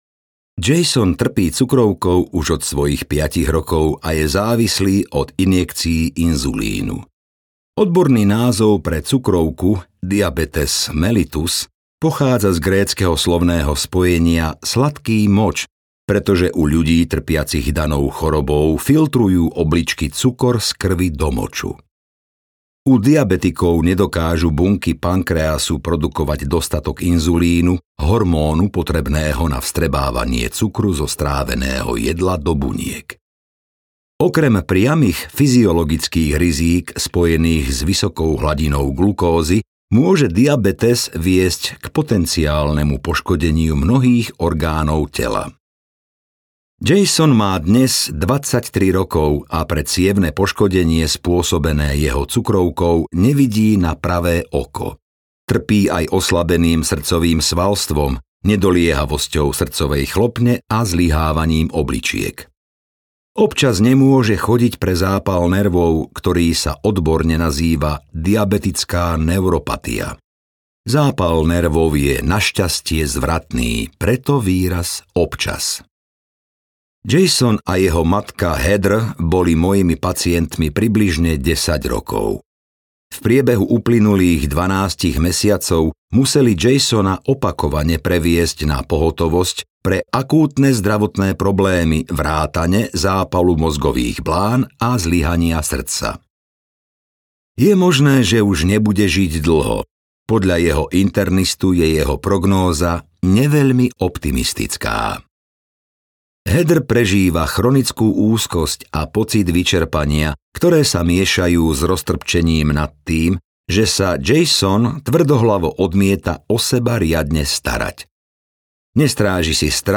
Audiokniha Keď telo povie nie - Gábor Maté | ProgresGuru